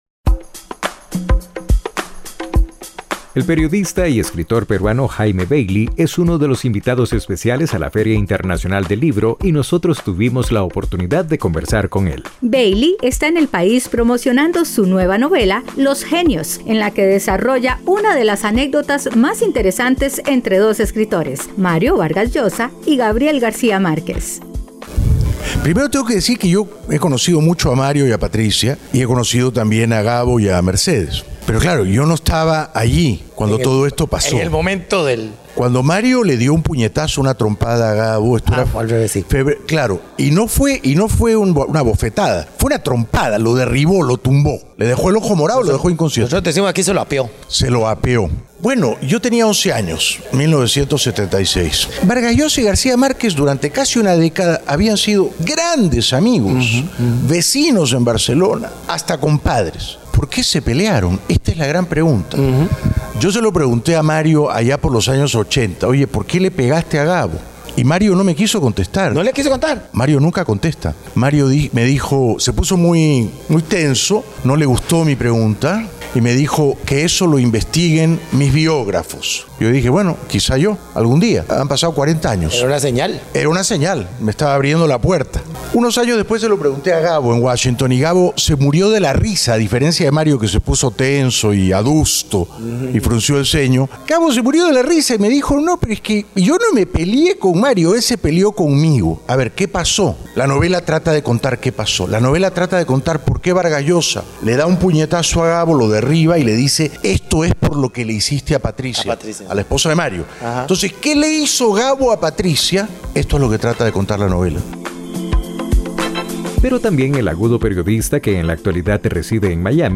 Entrevista a Jaime Bayly
El periodista y escritor peruano, Jaime Bayly es uno de los invitados especiales a la feria internacional del libro y nosotros tuvimos oportunidad de conversar con él.